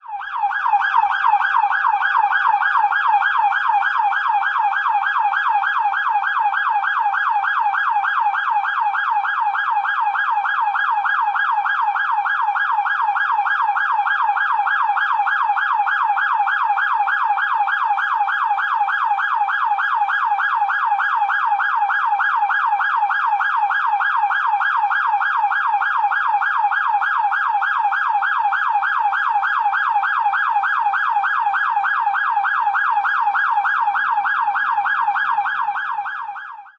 Police Siren: Various Types From Idle Car.